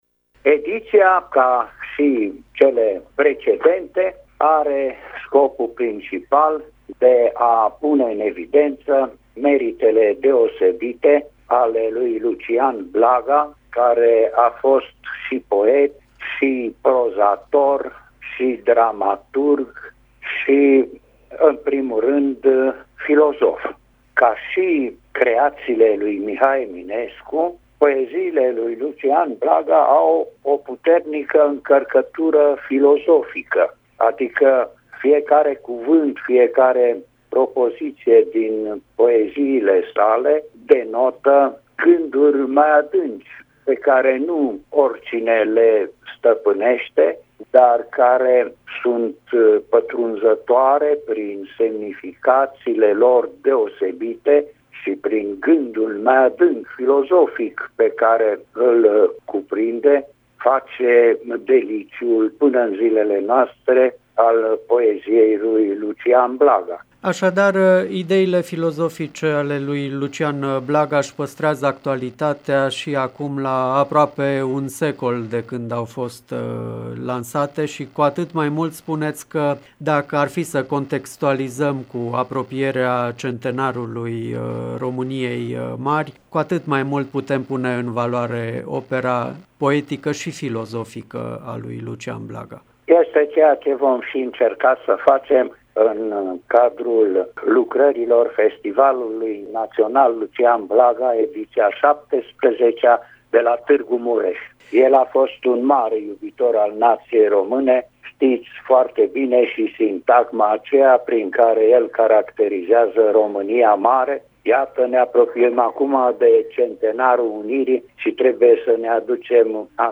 Unul dintre moderatorii evenimentului, academicianul Alexandru Surdu, vicepreședintele Academiei Române, a ținut să sublinieze actualitatea filosofiei lui Lucian Blaga într-un interviu